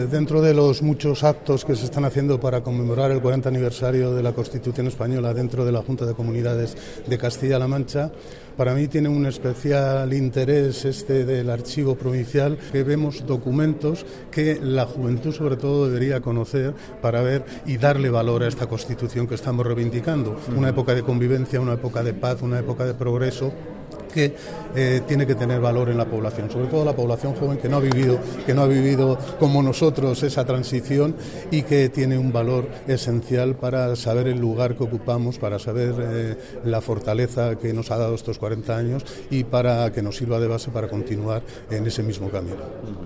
El director provincial de Educación, Cultura y Deportes en Guadalajara, Faustino Lozano, habla sobre la exposición conmemorativa del 40 aniversario de la Constitución organizada por el Gobierno regional y que puede visitarse en el Archivo histórico provincial de Guadalajara hasta el 7 de diciembre.